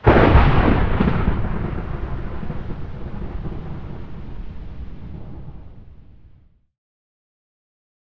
thunder3.ogg